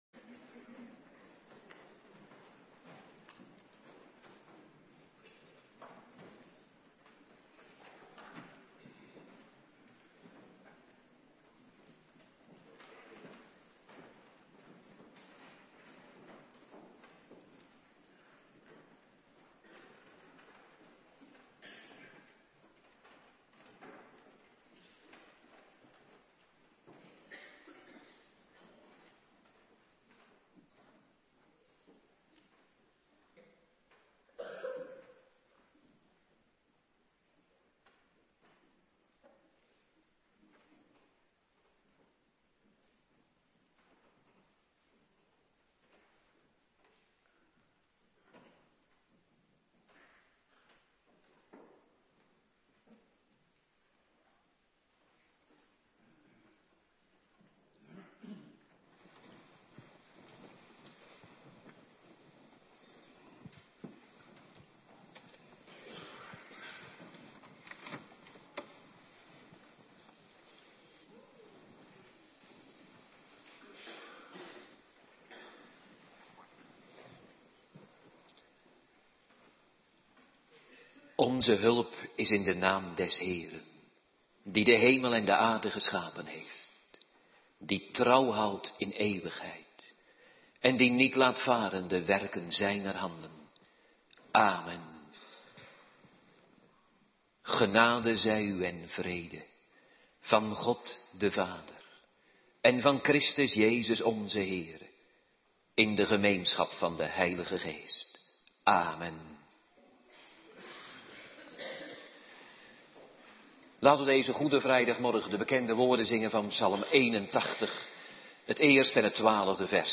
Morgendienst Goede Vrijdag
09:30 t/m 11:00 Locatie: Hervormde Gemeente Waarder Agenda